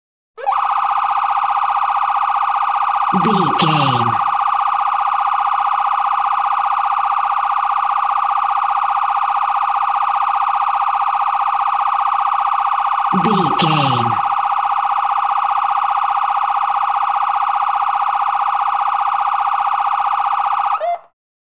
Ambulance Ext Stress Siren 90
Sound Effects
urban
chaotic
anxious
emergency